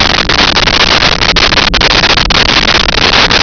Sfx Holosteady Loop3
sfx_holosteady_loop3.wav